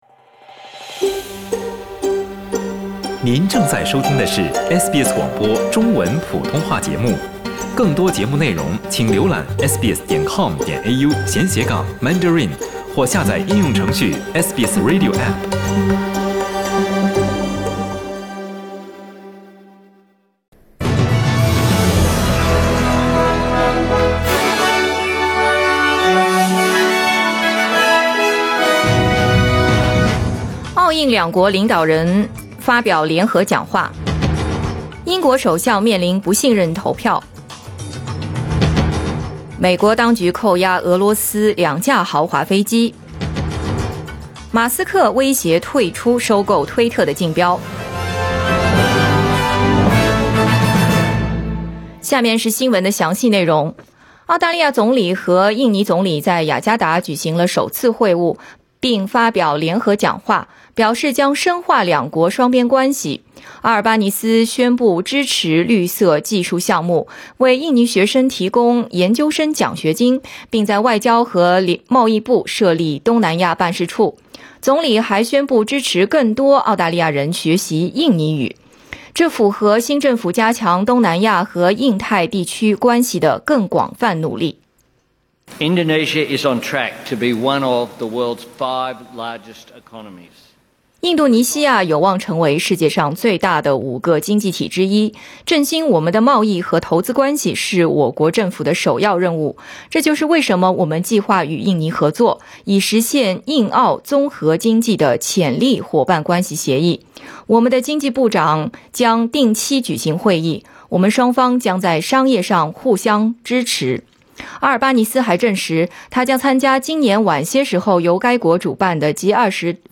SBS早新闻 (2022年6月7日)